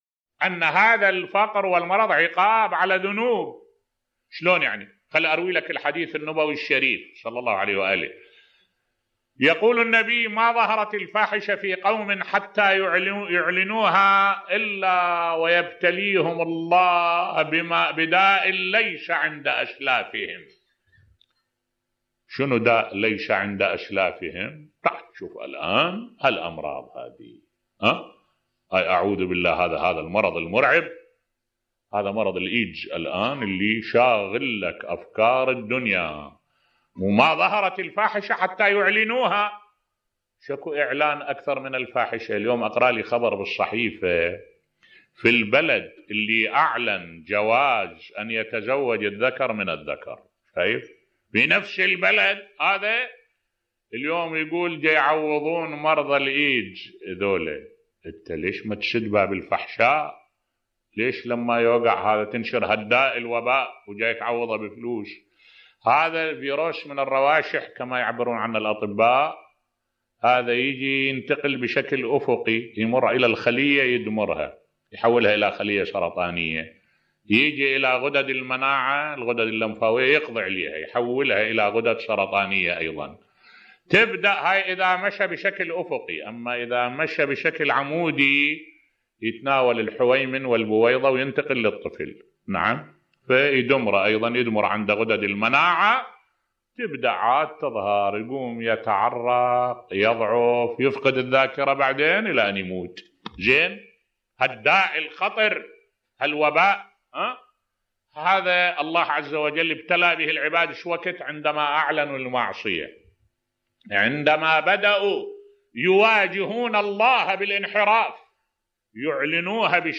ملف صوتی انتشار الفاحشة يولّد الوباء والفقر بصوت الشيخ الدكتور أحمد الوائلي